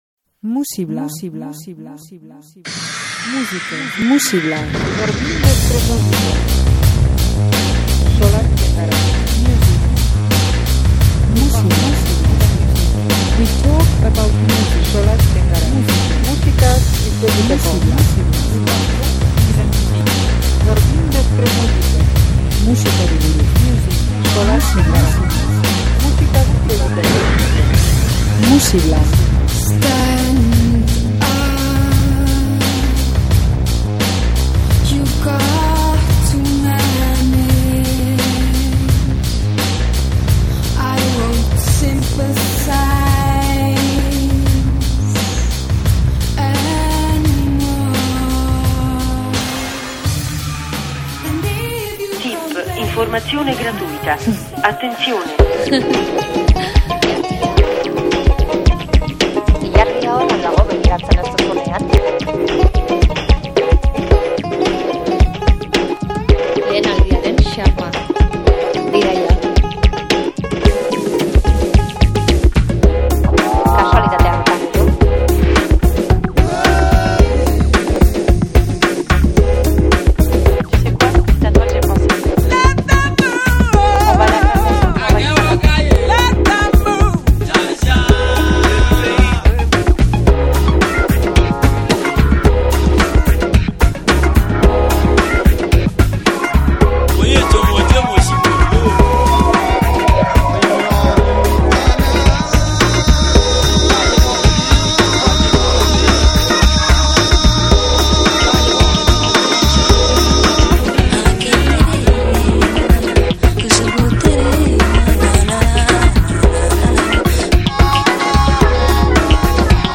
bossa eta soinu brasildarrez atonduriko disko txundigarria.